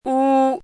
chinese-voice - 汉字语音库
wu1.mp3